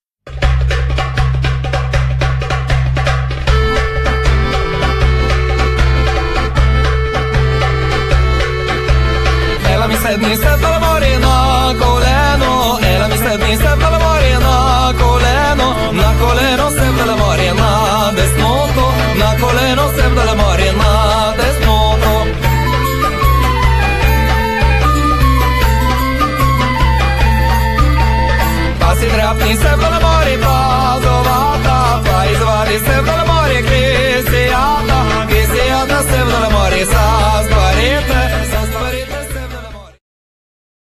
akordeon, śpiew, instrumenty perkusyjne
darabuka, bendir
śpiew, buzuki, tambura, instrumenty perkusyjne
flety
Nagranie: TR Studio, Warszawa; czerwiec 2005